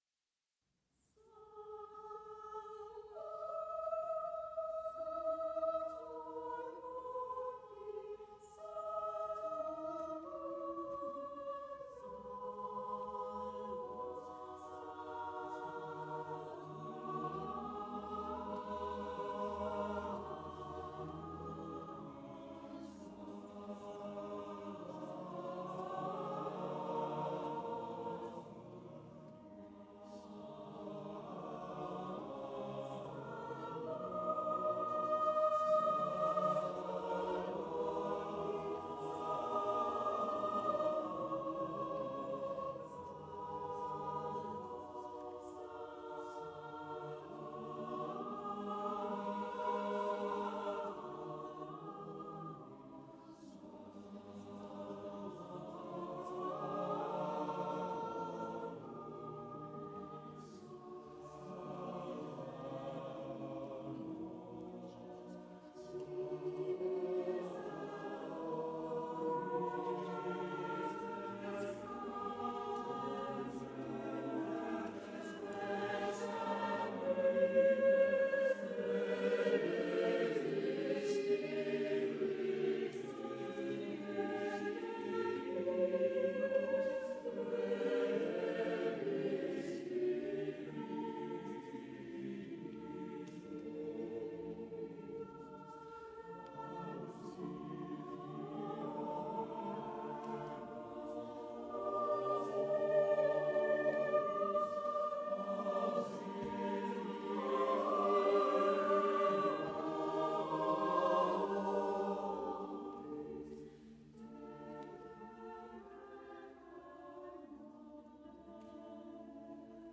Even the accompaniment may be a joke, being scored for piano and harmonium) Renaissance Choir 1999 ( 28 min )